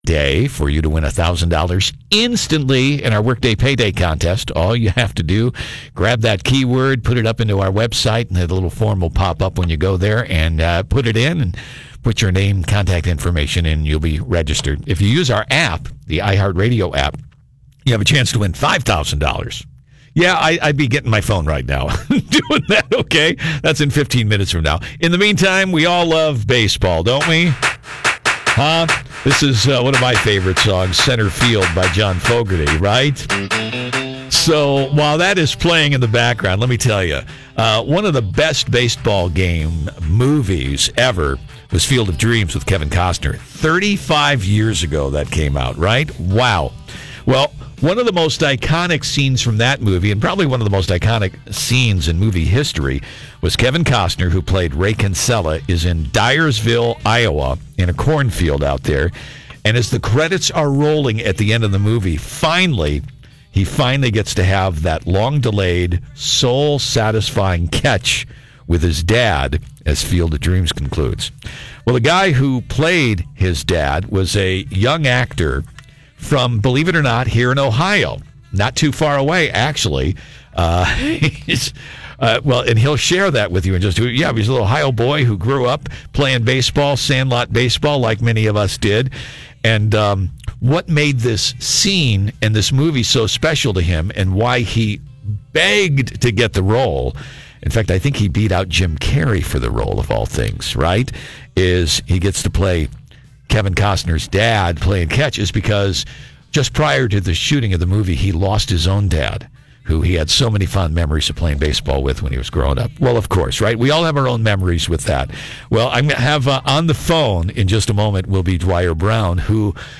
INTERVIEW https